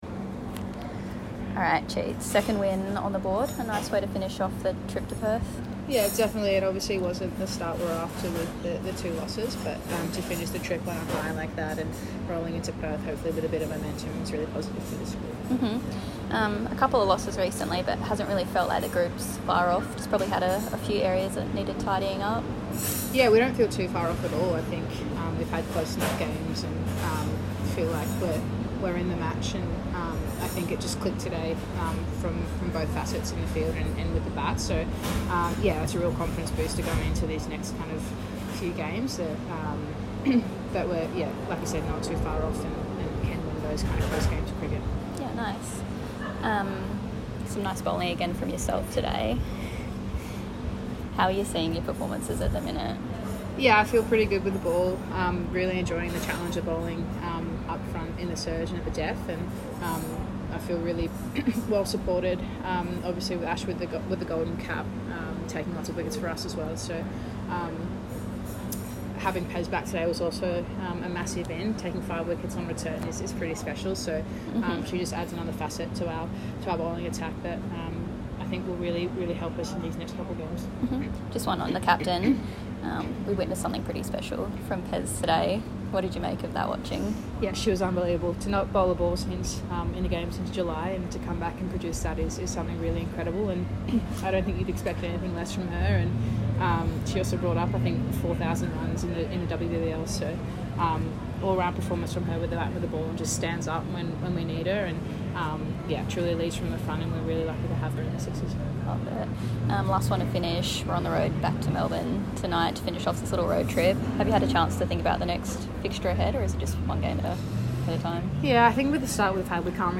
Sydney Sixers quick Lauren Cheatle (2-14) speaking post Sixers 7-wicket win over the Melbourne Renegades at the WACA. (CA)